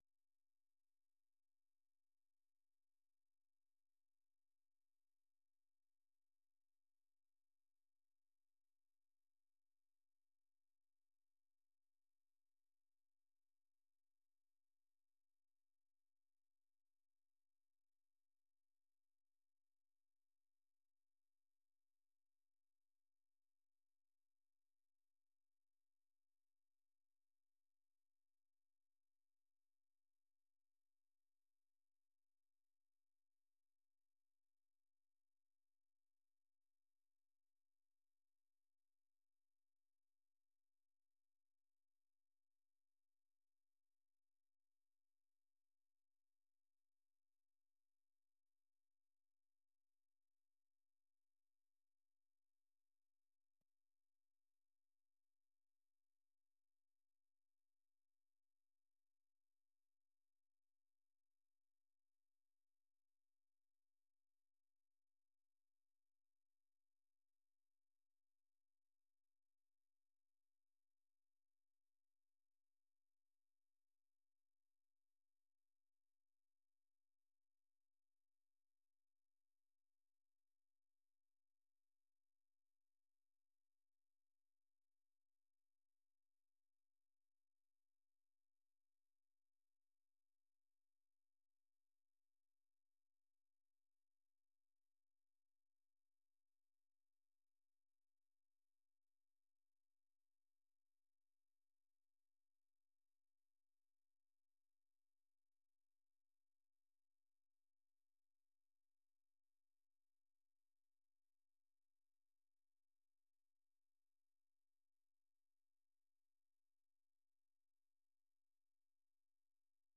생방송 여기는 워싱턴입니다